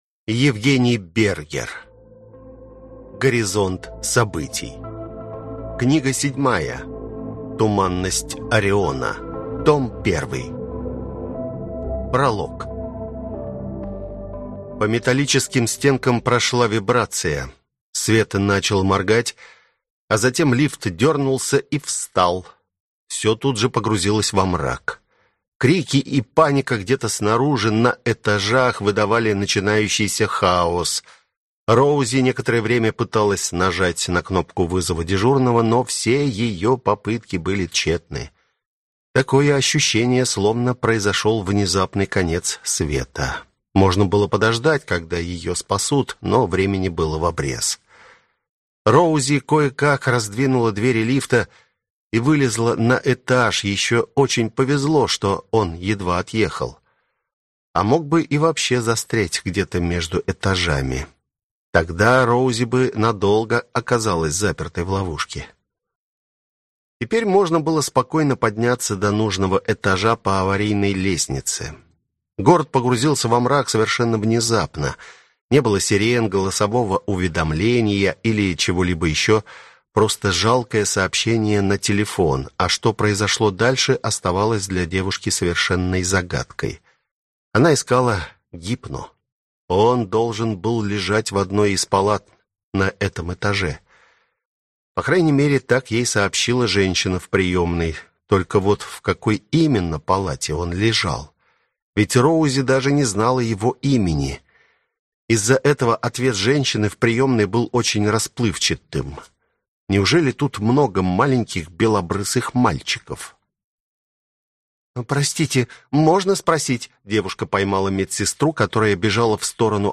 Аудиокнига Горизонт событий. Книга 7. Туманность Ориона. Том 1 | Библиотека аудиокниг